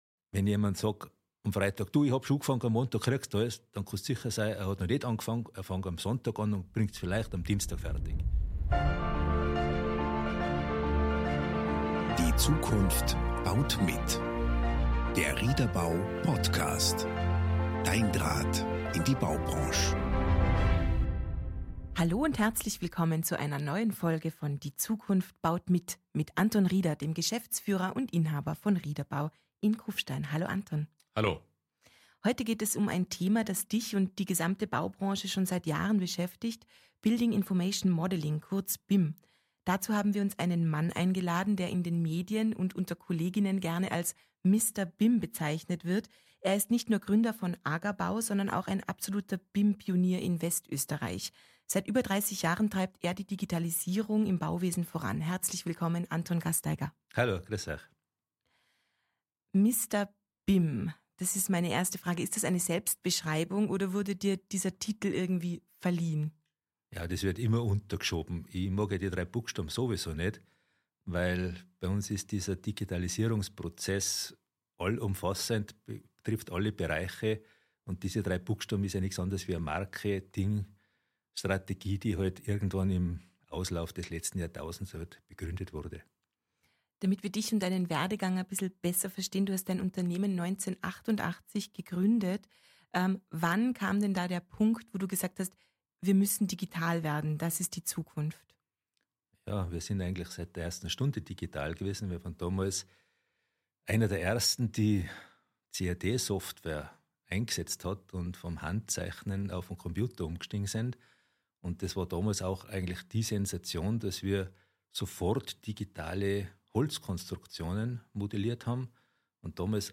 Eine spannende Diskussion über Effizienz, Standardisierung und die Evolution des Bauhandwerks in einer zunehmend digitalen Welt.